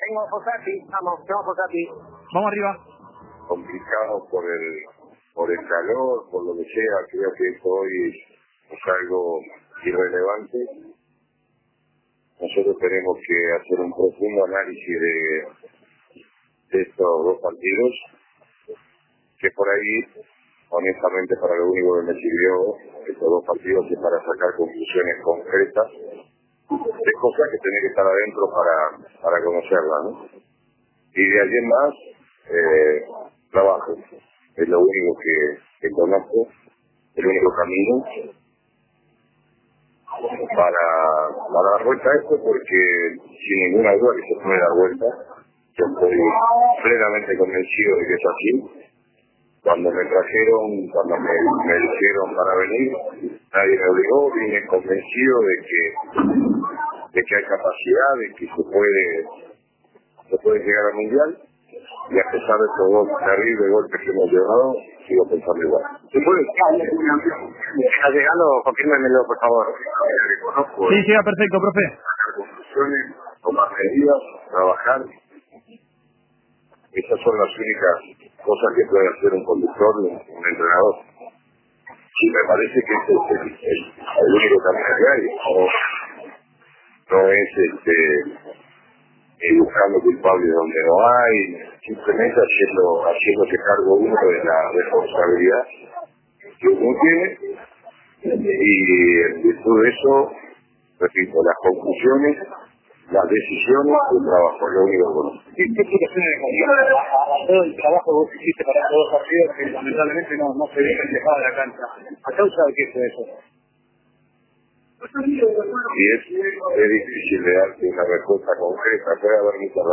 Fossati en los vestuarios de Barranquilla, tras la goleada.